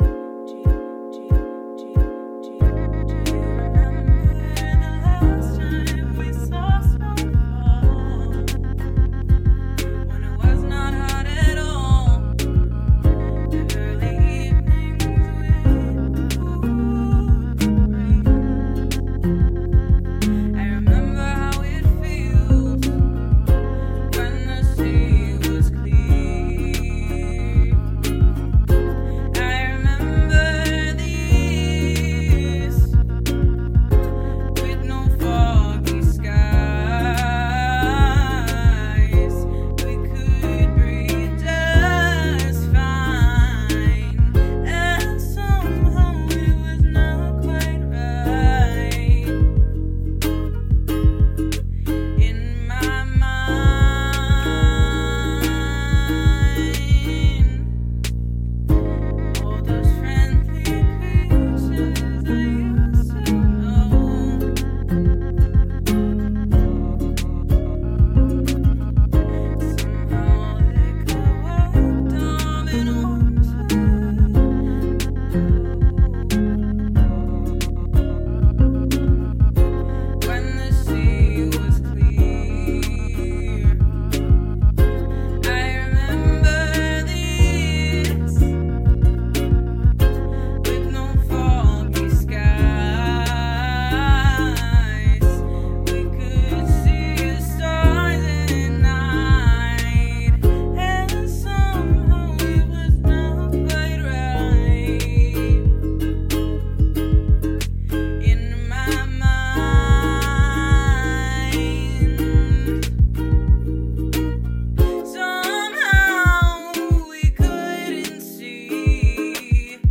Lyrics and vocals